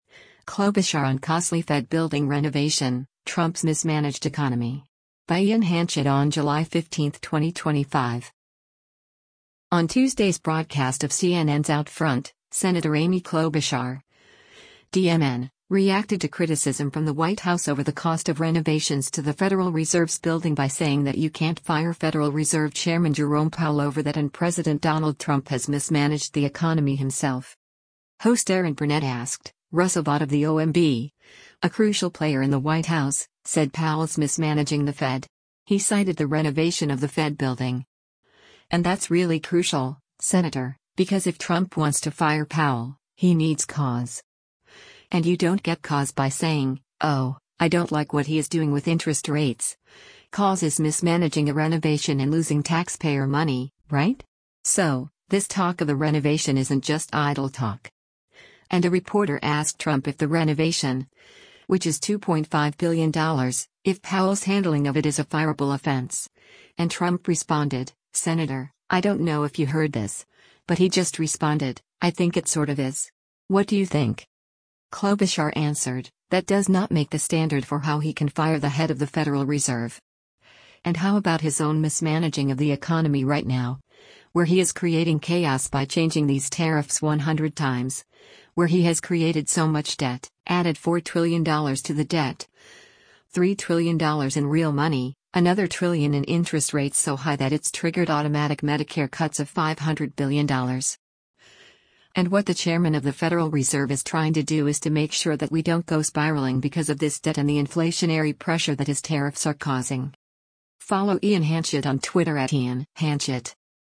On Tuesday’s broadcast of CNN’s “OutFront,” Sen. Amy Klobuchar (D-MN) reacted to criticism from the White House over the cost of renovations to the Federal Reserve’s building by saying that you can’t fire Federal Reserve Chairman Jerome Powell over that and President Donald Trump has mismanaged the economy himself.